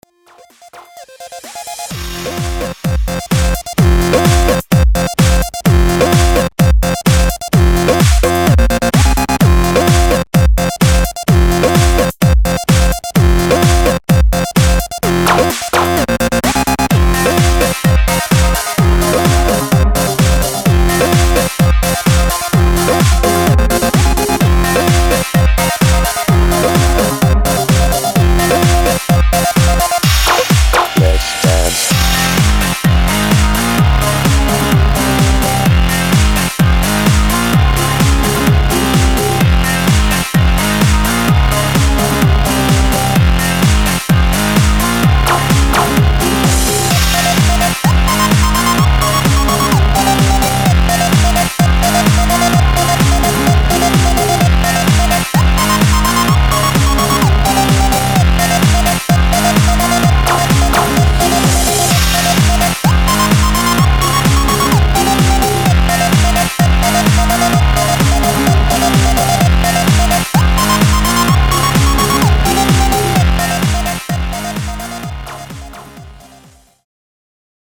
L'espece de refrain rock/electro avec les synth qui petent de partout j'ai envie de dire jolie.
Je n'aime pas la voix robotisé à la con qui te dit de danser et je ne sais plus
que cela sonne trop electro dance avec les bras qui font la moulinettes BREF